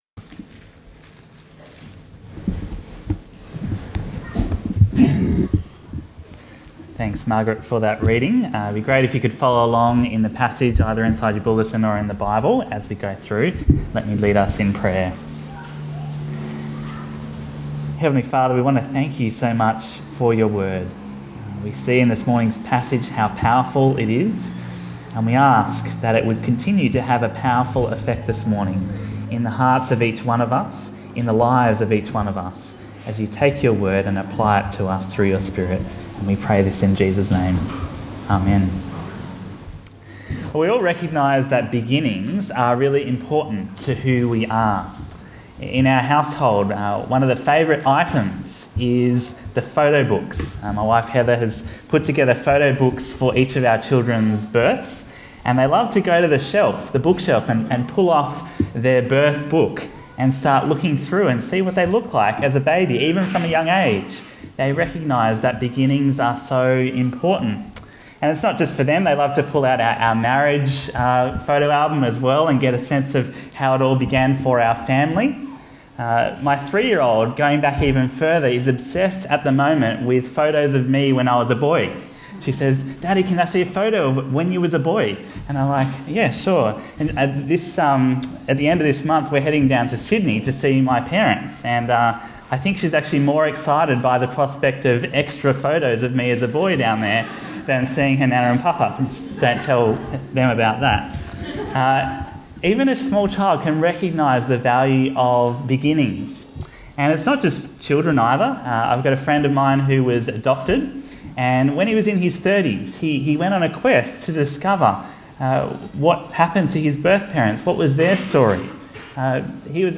Bible Talks Bible Reading: Genesis 1-2:4